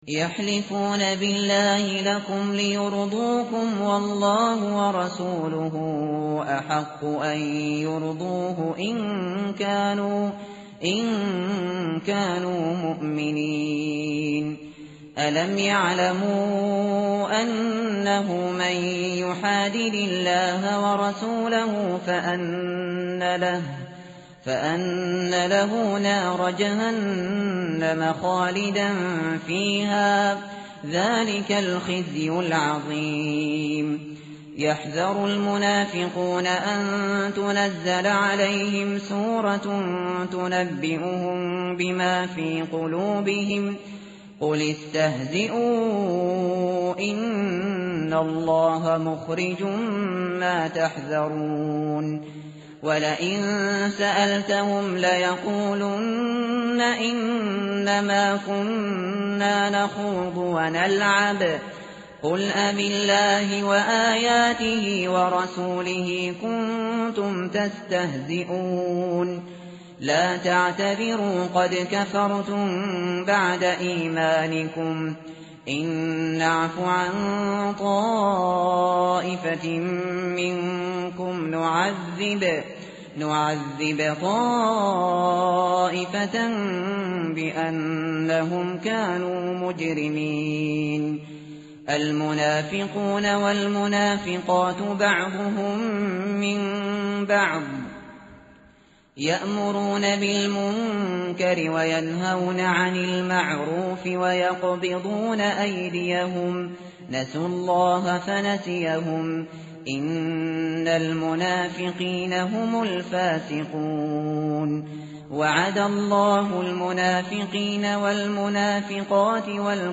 tartil_shateri_page_197.mp3